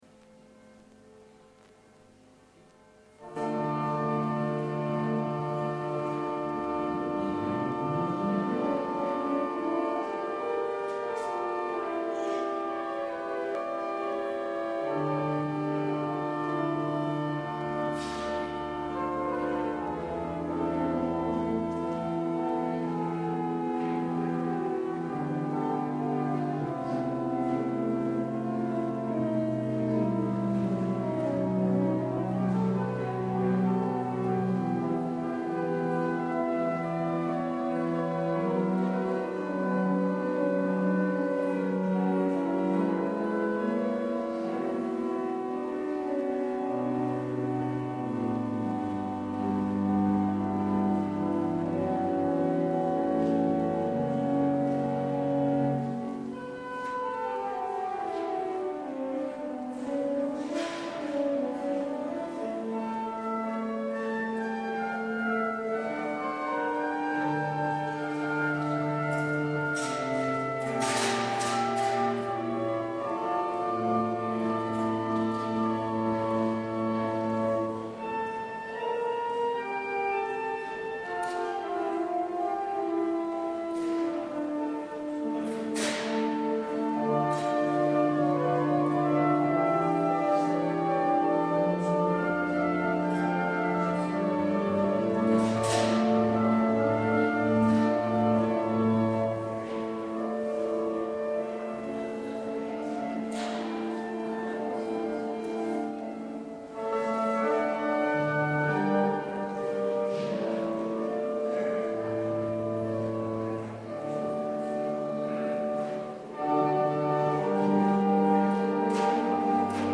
Preek 7e zondag, door het jaar A, 19/20 februari 2011 | Hagenpreken